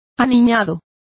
Complete with pronunciation of the translation of childish.